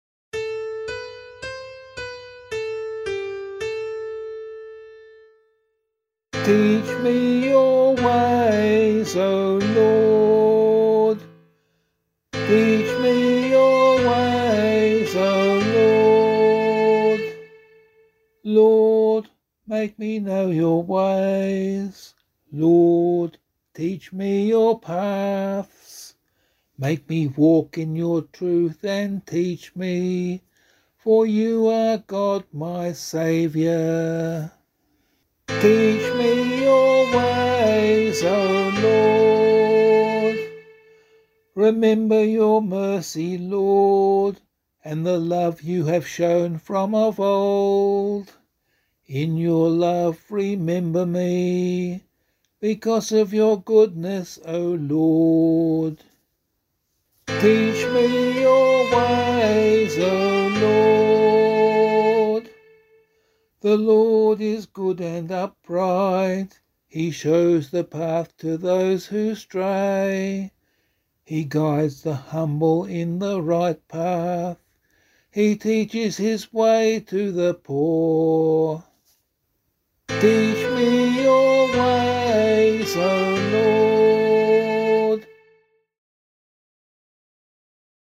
037 Ordinary Time 3 Psalm B [LiturgyShare 4 - Oz] - vocal.mp3